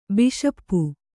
♪ biṣappu